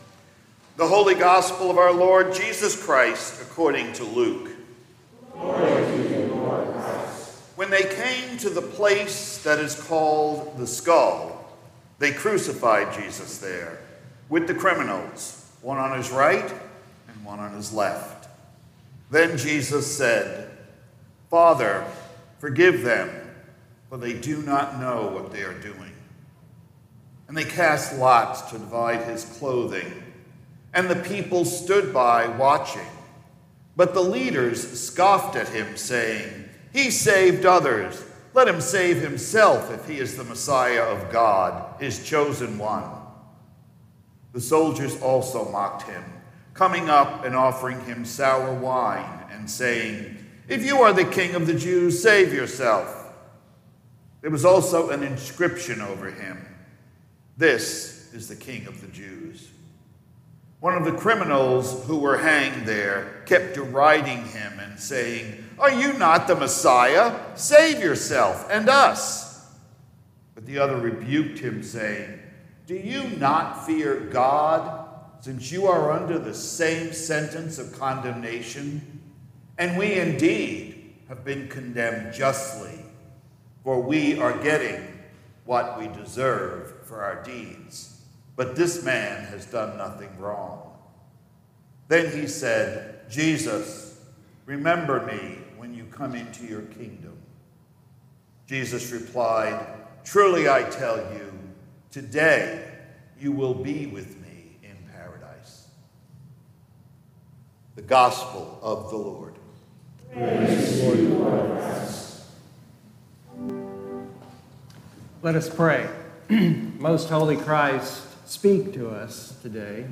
Latest Sermons & Livestreams